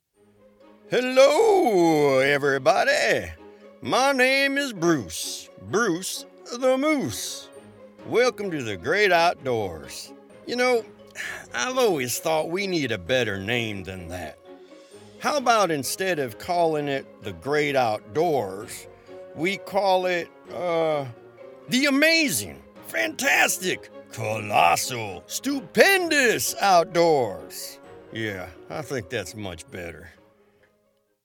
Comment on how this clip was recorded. • Broadcast-quality audio